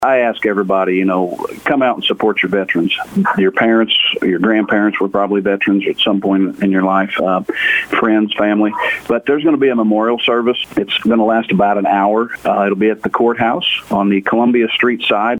St. Francois County Sheriff, Jeff Crites says he invites everyone to be at the event and honor our veterans.